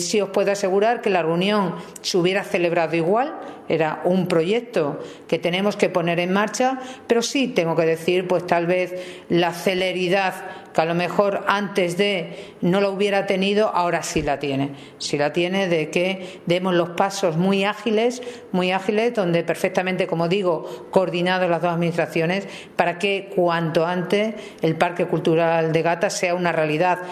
CORTES DE VOZ
Charo_ReunixnParqueGata.mp3